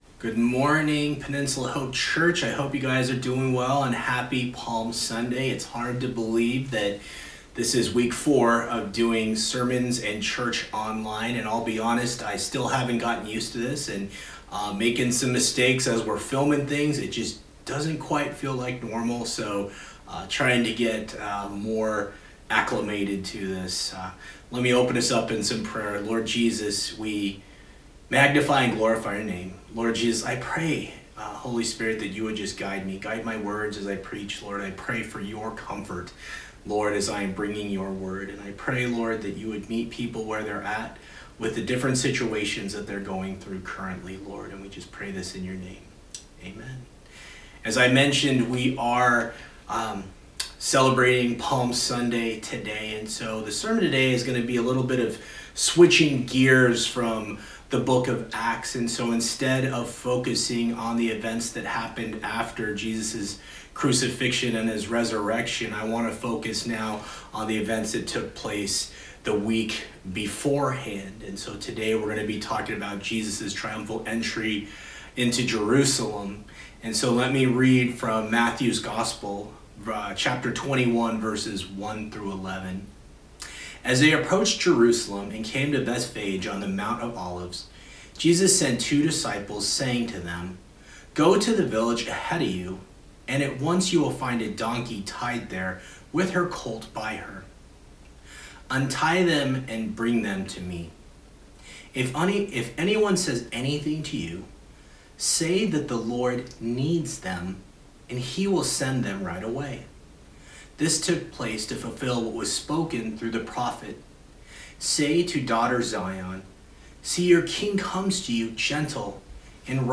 April 5, 2020 Sunday Message, Matthew 21:1-11 Jesus’ triumphal entry